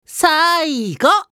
少年系ボイス～戦闘ボイス～